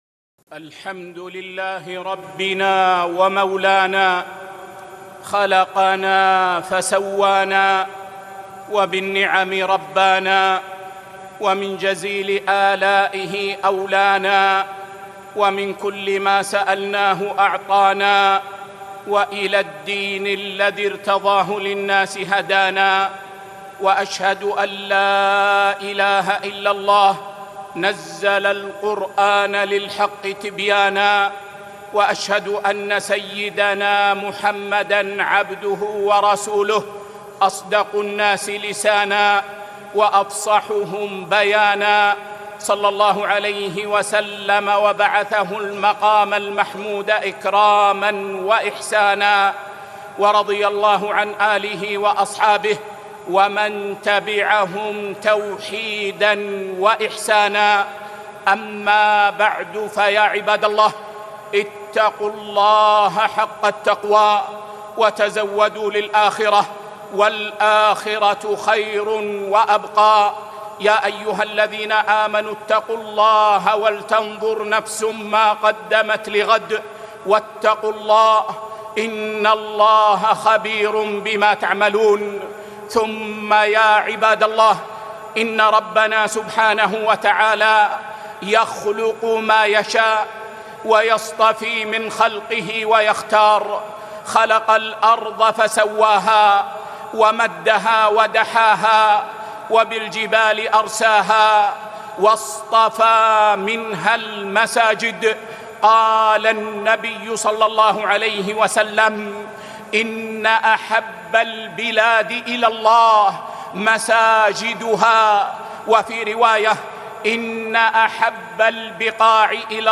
خطبة - بيوت الله